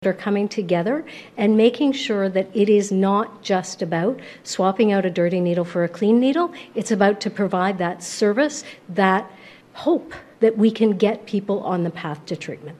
jones-response-3.mp3